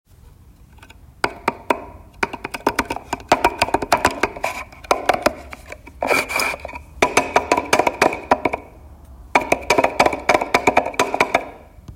Wooden_box.mp3